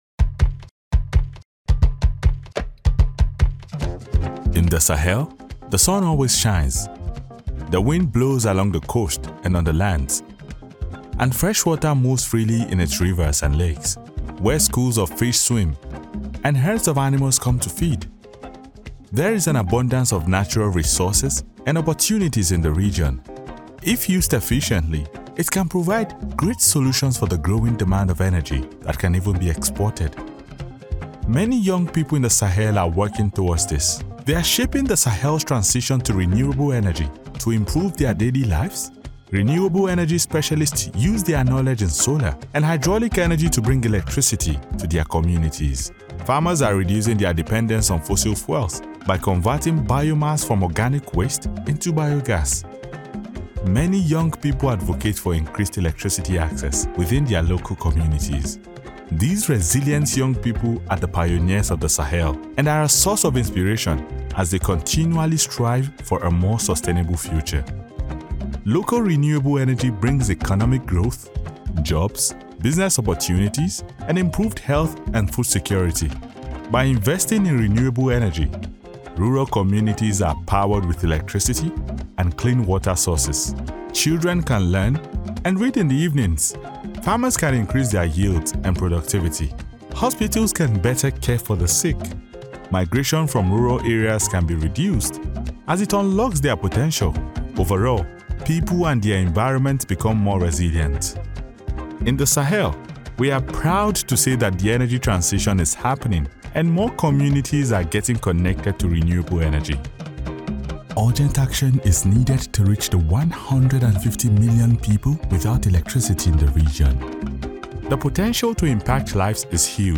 Male
Assured, Authoritative, Bright, Character, Confident, Conversational, Corporate, Deep, Energetic, Engaging, Friendly, Gravitas, Natural, Upbeat, Versatile, Warm
General African English accent , Nigerian English accent, West African accent,
Voice reels
Microphone: Senheisser MKH 416